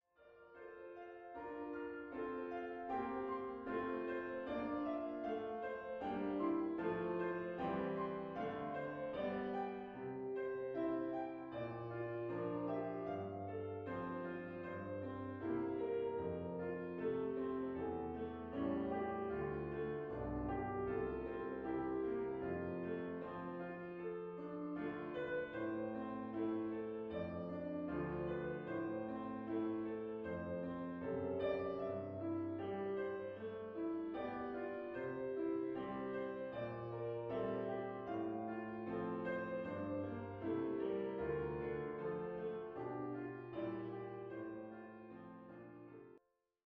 Klavier-Sound